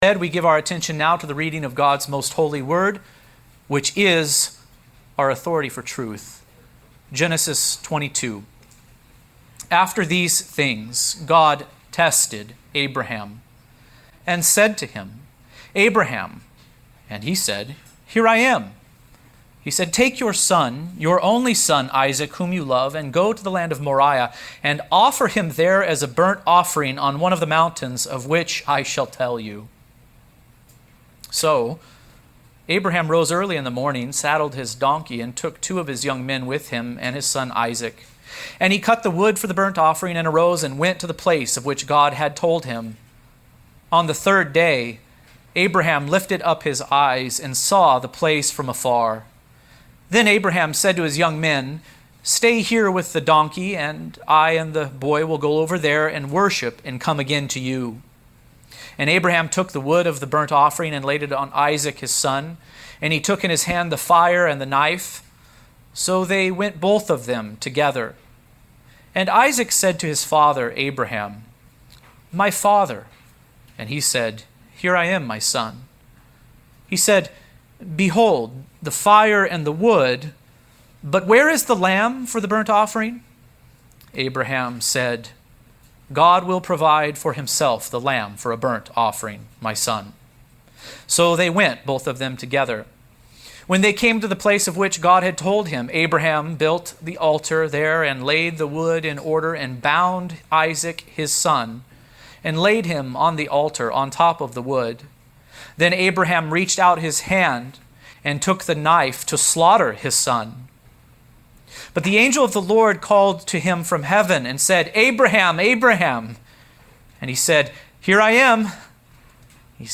The LORD Will Provide | SermonAudio Broadcaster is Live View the Live Stream Share this sermon Disabled by adblocker Copy URL Copied!